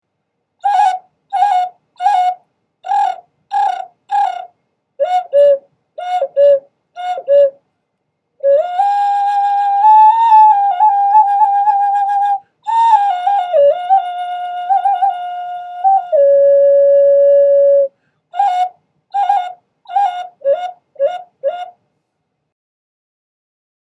toucan ocarina musical instrument flute
Toucan Ocarina hand Painted black pottery produces lovely melody
It is natural tuned and produces a lovely melody.
A recording of the sound of this particular ocarina is in the top description, just click on the play icon to hear the sound.